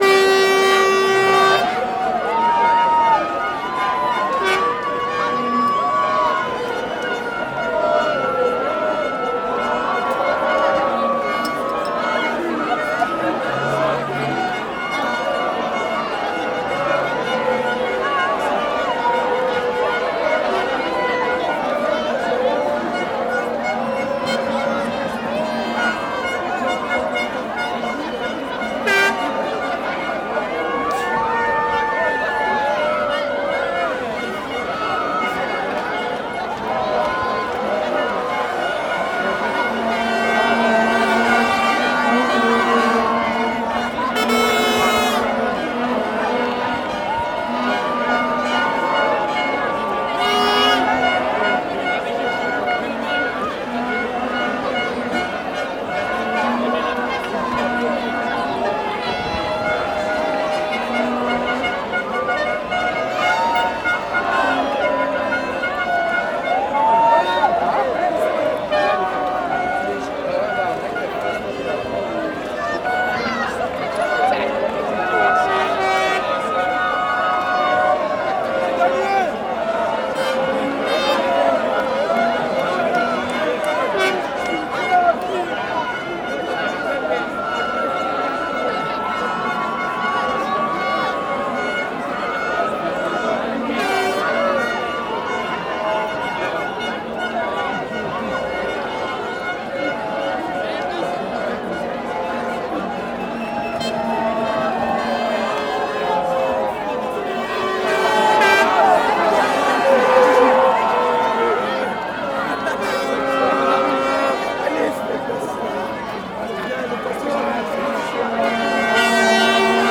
Soccer Supporters in the Street
celebration crowd cup football happy soccer supporter swiss sound effect free sound royalty free Memes